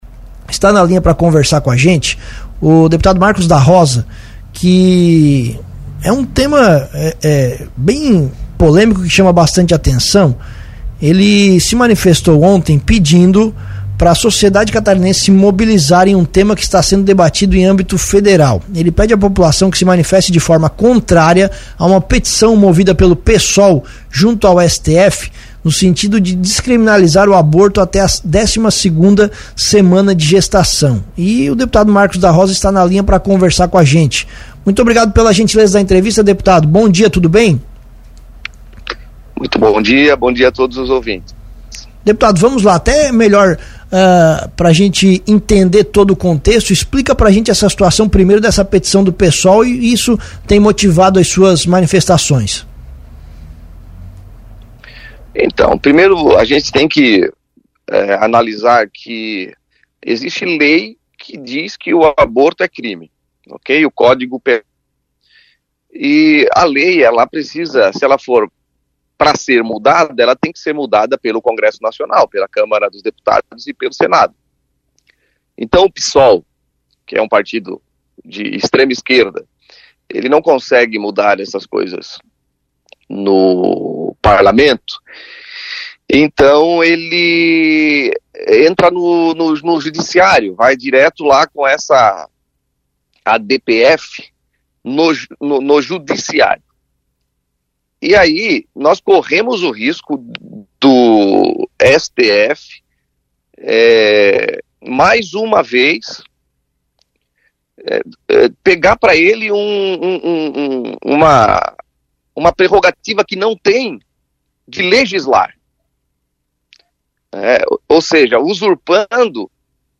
Durante entrevista ao Cruz de Malta Notícias desta sexta-feira (22) o deputado Marcos da Rosa falou sobre o tema.